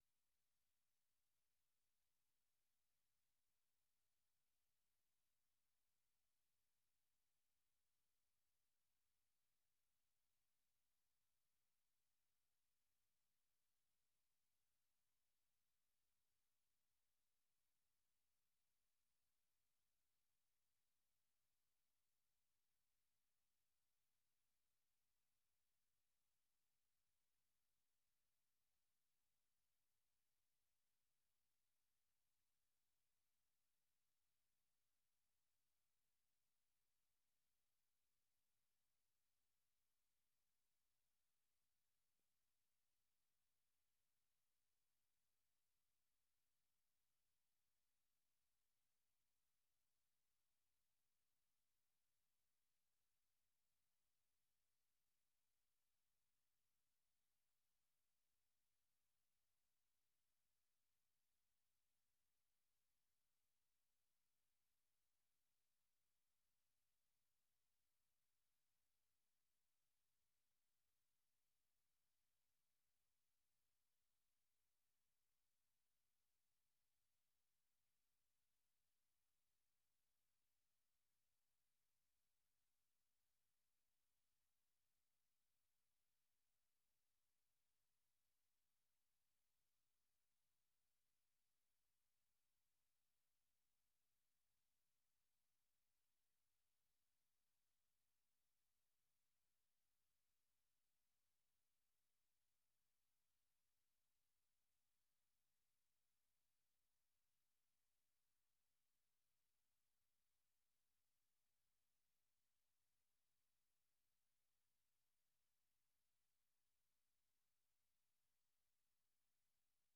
Nûçeyên 3’yê paşnîvro
Nûçeyên Cîhanê ji Dengê Amerîka